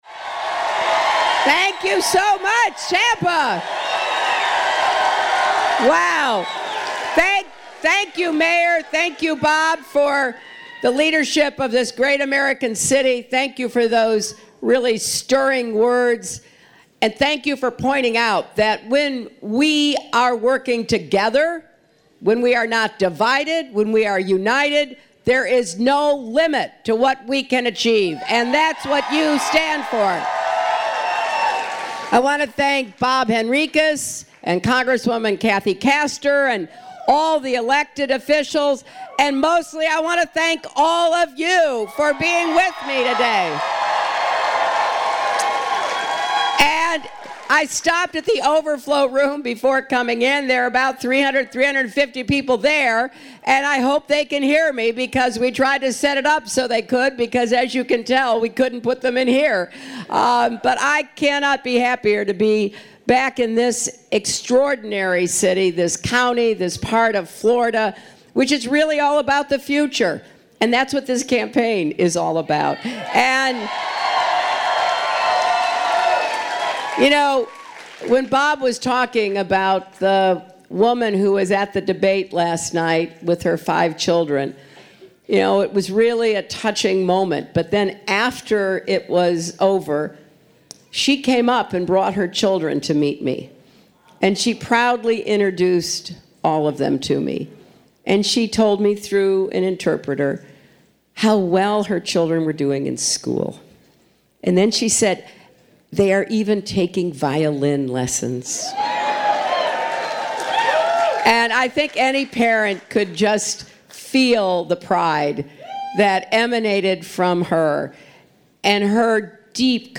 Presidential candidate Hillary Clinton packed the RitzTheatrein Tampa'sYborCity at lunchtime Thursday, taking about issues such as climate change and solar power and finding money to rebuild ports, roads and rail.
Clinton was introduced by Tampa Mayor Bob Buckhorn.
hillary_speech.mp3